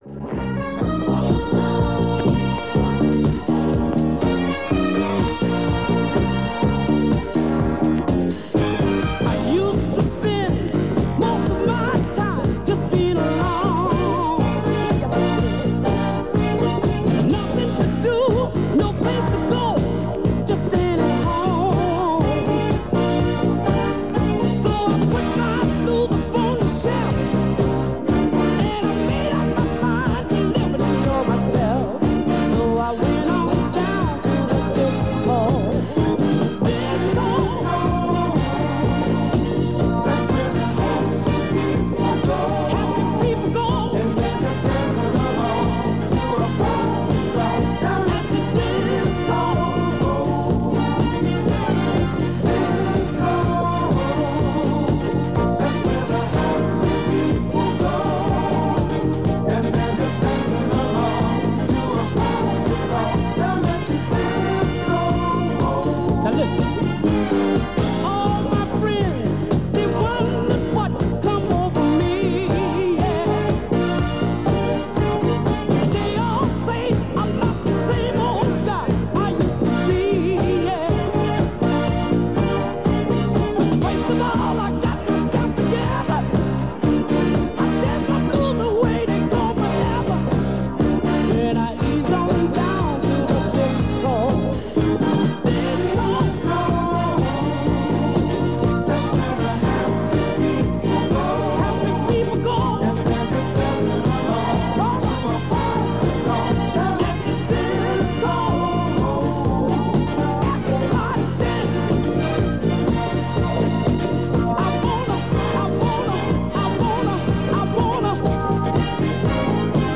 The tape is from the Anoraks Ireland Collection and was made from 102 FM between 1203-1249 on Sunday 27th December 1987.